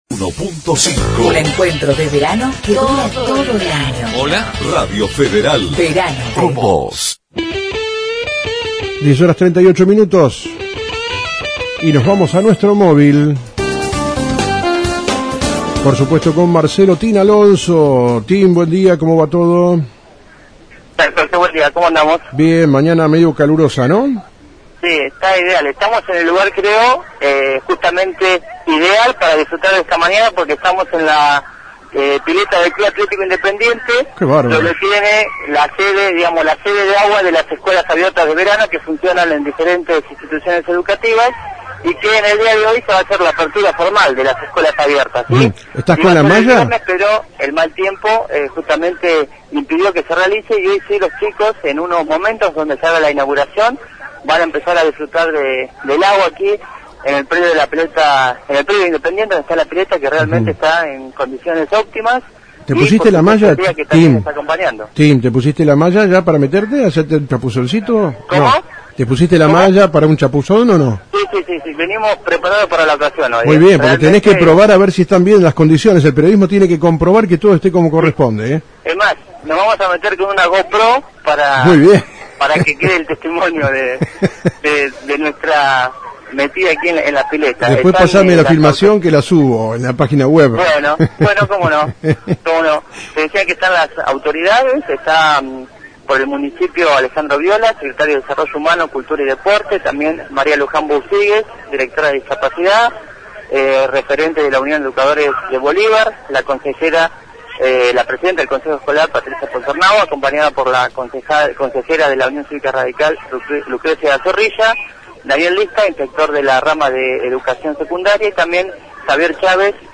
Acto de Apertura